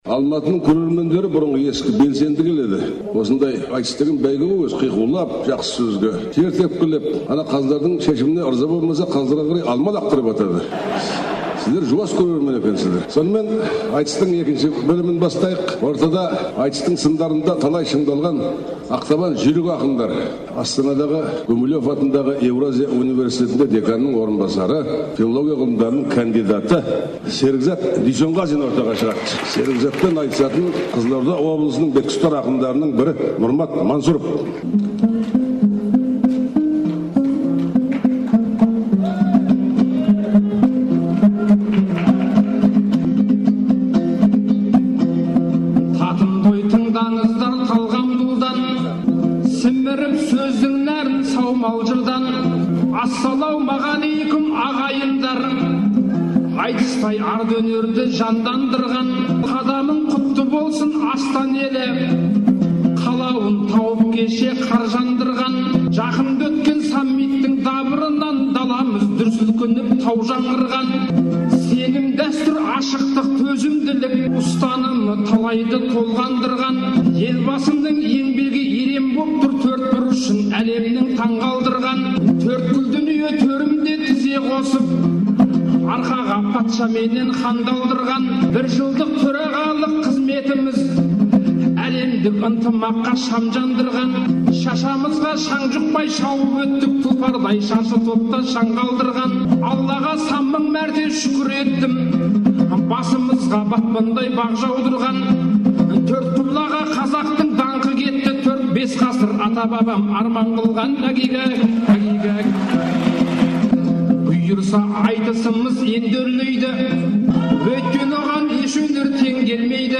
«Тәуелсіздік тағылымы» жыр сайысына